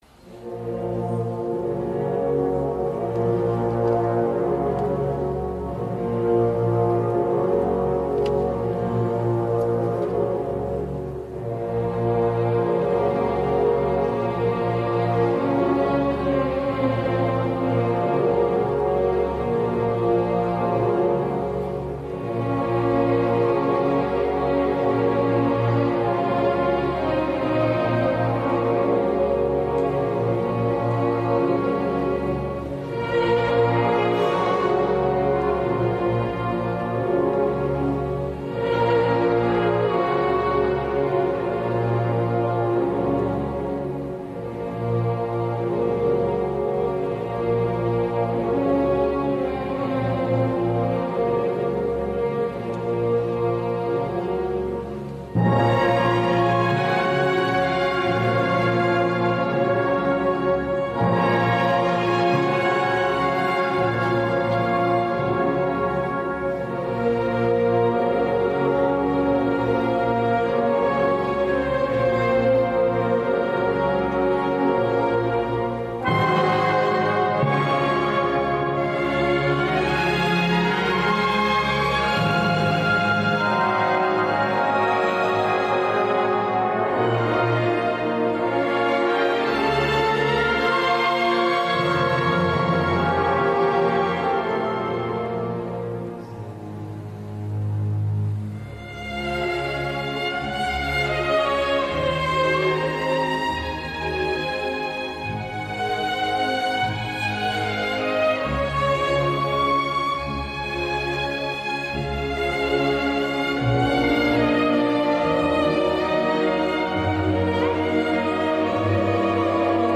Похоронный марш для оркестра